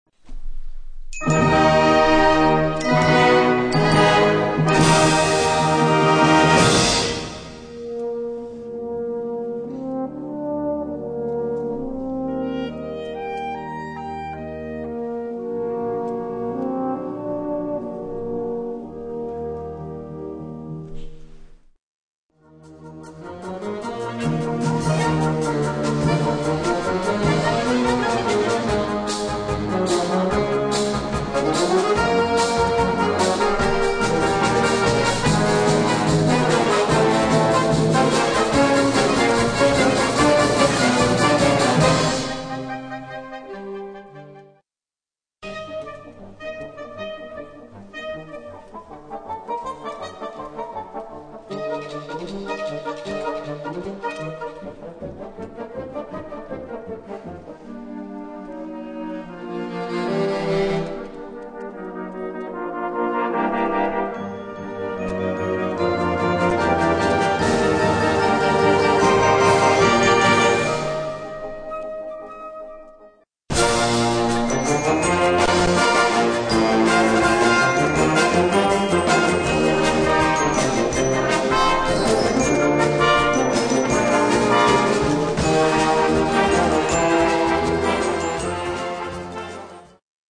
Gattung: Overture
6:40 Minuten Besetzung: Blasorchester Zu hören auf